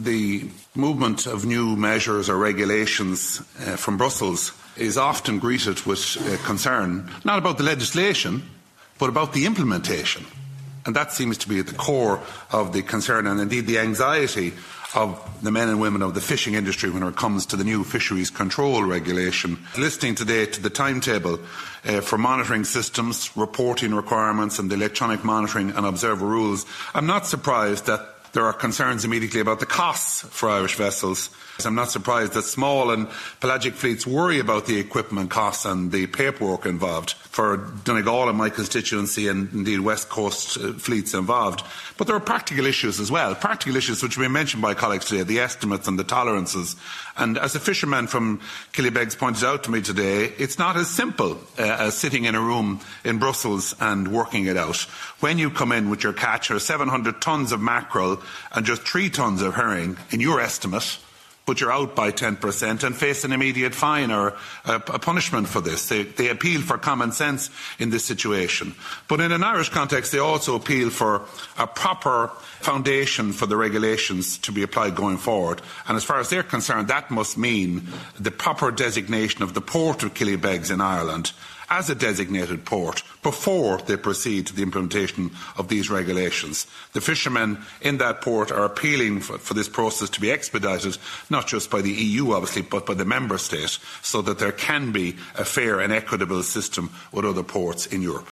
The issue was raised at the European Parliament’s Committee on Fisheries by Midlands North West MEP Ciaran Mullooly, who told the committee that what fishers in Ireland are seeking is common sense, and an understanding at official level of how these things work in real life.